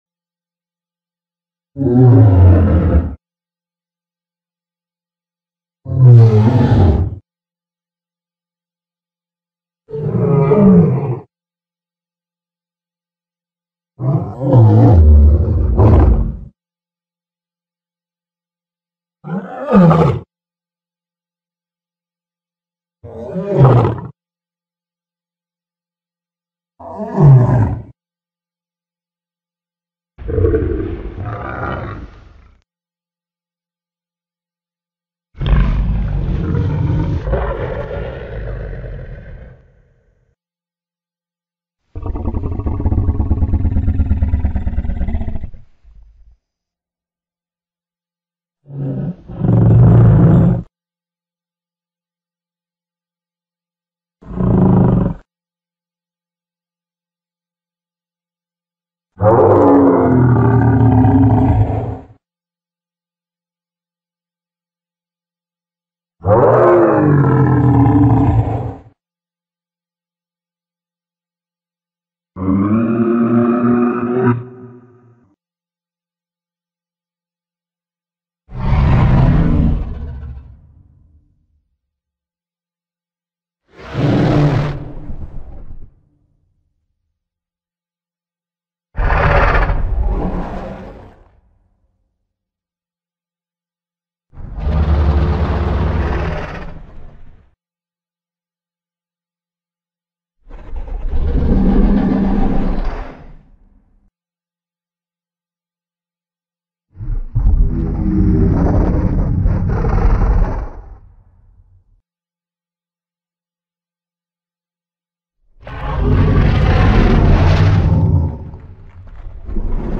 The Sound Effects Of Ankylosaurus